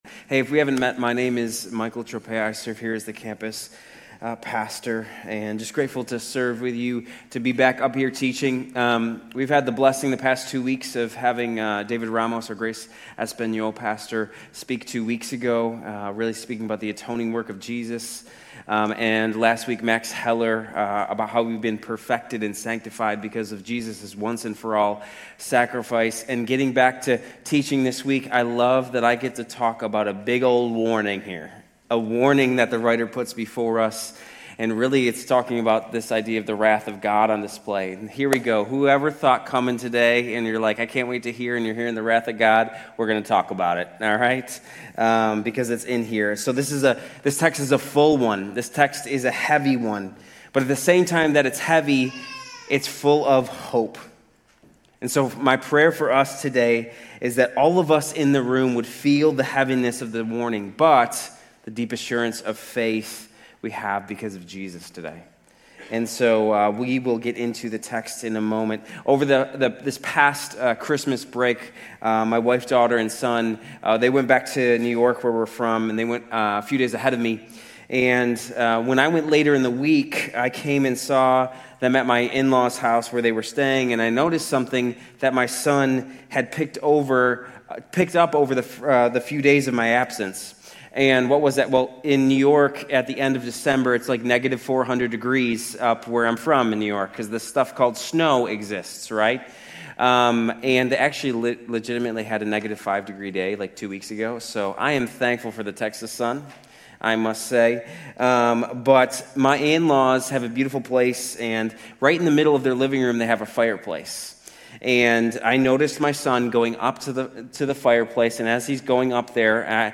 Grace Community Church University Blvd Campus Sermons 2_22 University Blvd Campus Feb 23 2026 | 00:29:16 1x 00:00 / 00:29:16 Subscribe Share RSS Feed Share Link Embed